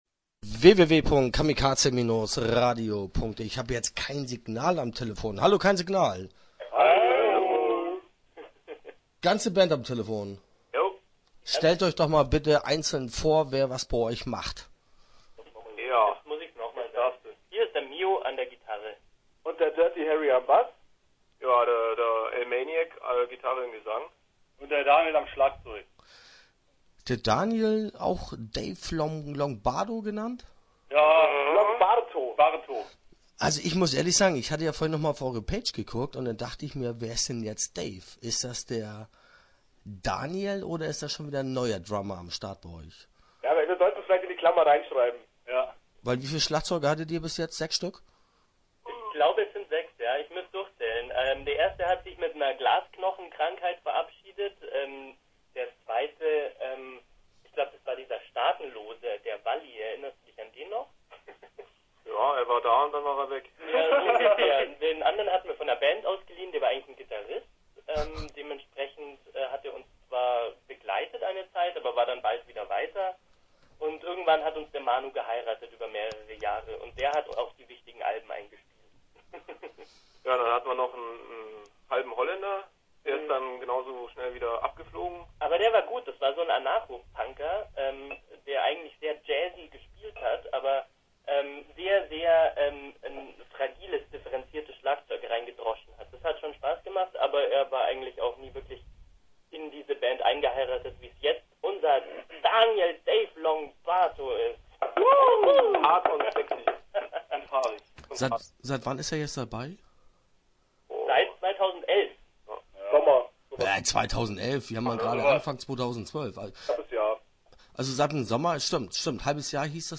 Interview Teil 1 (12:39)